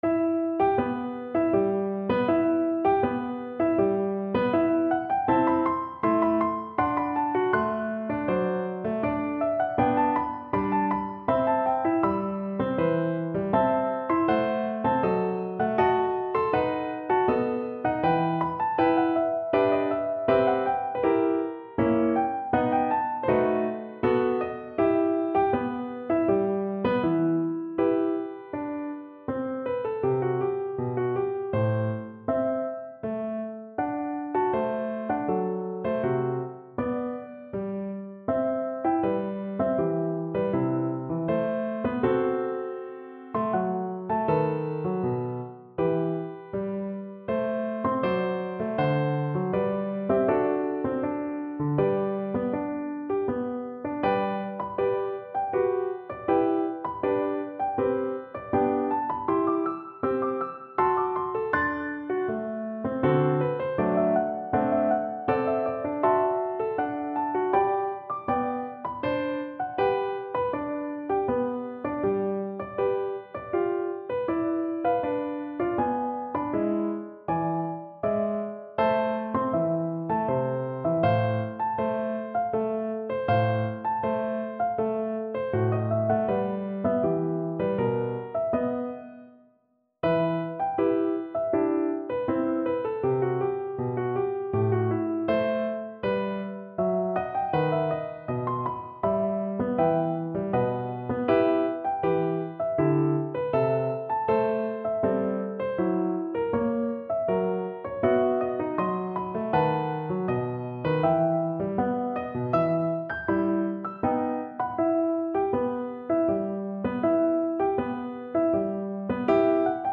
Flute version
3/4 (View more 3/4 Music)
Andante
Classical (View more Classical Flute Music)